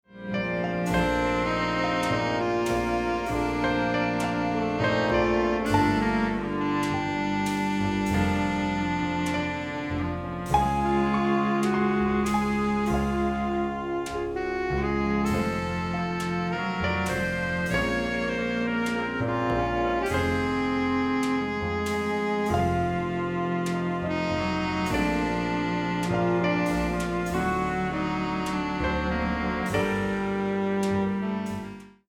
No stress, just soft and relaxed music to your podcast.